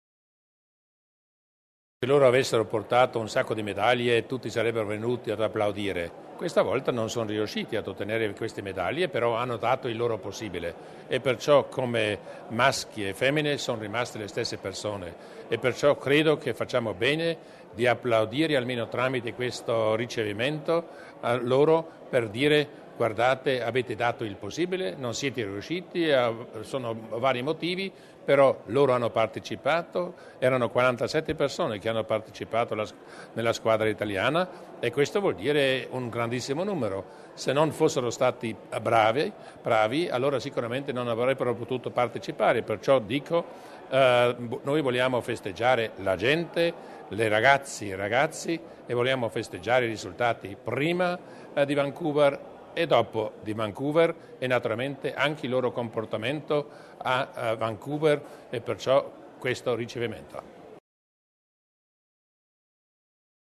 I 45 altoatesini che, a Vancouver, sono riusciti a realizzarlo, sono stati ricevuti questo pomeriggio (9 aprile) alla cantina nella roccia di Laimburg dal presidente della giunta provinciale, Luis Durnwalder.